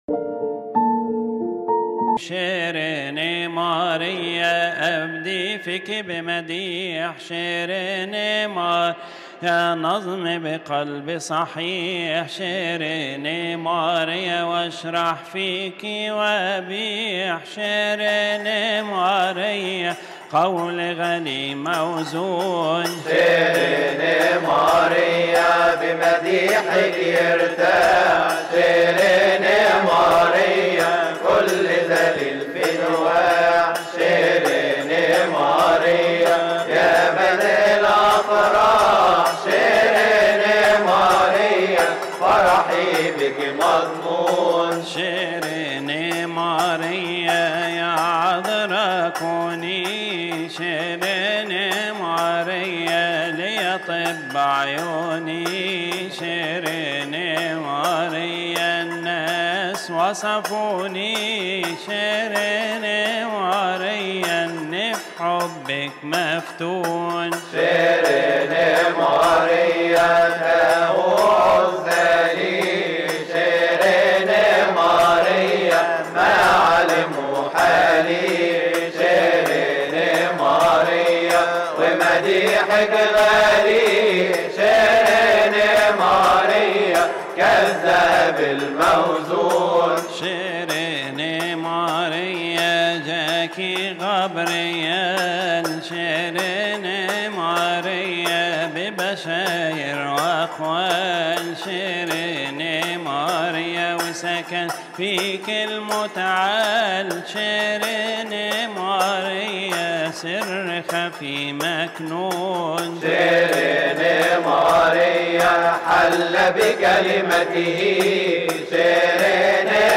لحن شيريه نيه ماريا
المصدر : الأنبا أنطونيوس مطران القدس يحتوي هذا التسجيل علي: مديح كيهكي علي القطعة الثامنة من ثيؤطوكية الاحد يقال في تسبحة نصف الليل بشهر كيهك. مديح كيهكي علي القطعة الثامنة من ثيؤطوكية الاحد المصدر: الأنبا أنطونيوس مطران القدس اضغط هنا لتحميل اللحن